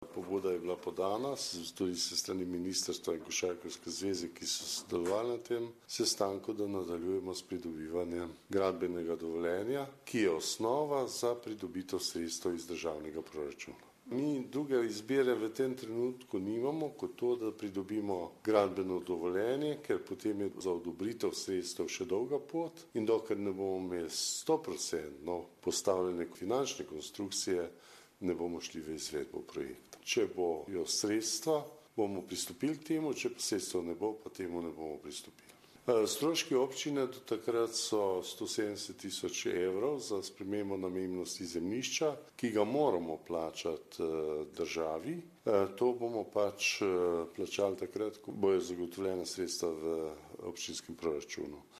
Župan Alojzij Muhič o zadnjih pogovorih v zvezi z izgradnjo Mestne večnamenske dvorane Portoval